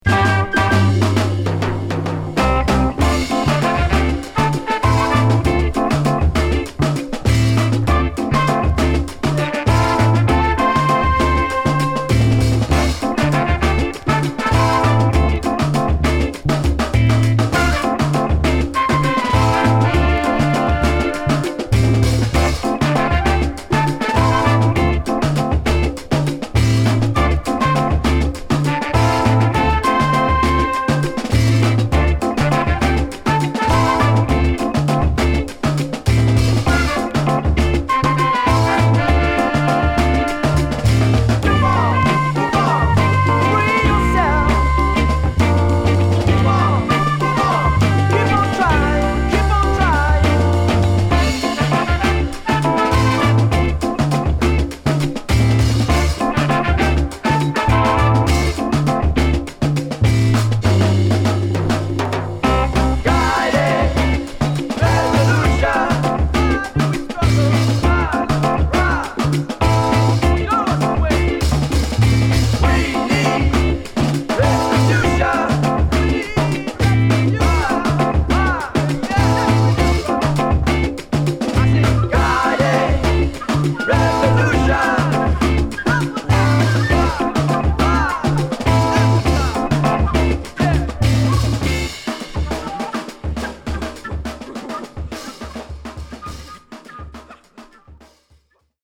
弾んだ激打ちドラムにホーンとベース／ギター、オルガンが絡むファンクチューン！